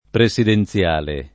[ pre S iden ZL# le ]